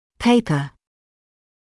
[‘peɪpə][‘пэйпэ]письменная работа, исследование (статья, научная работа, диссертация); бумага